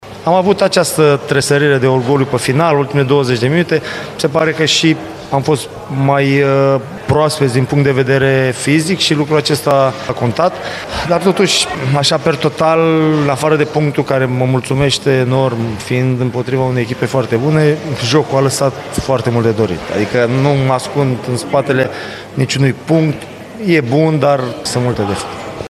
Antrenorul UTA-ei, Adrian Mihalcea, apreciază foarte mult spiritul de luptă arătat și punctul smuls adversarilor, dar avertizeaază că mai sunt multe amănunte de pus la punct: